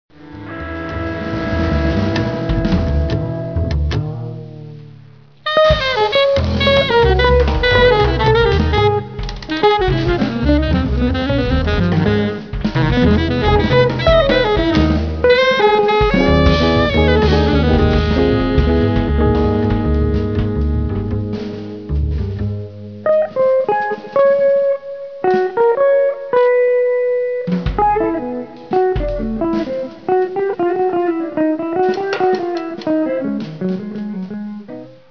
alto & soprano sax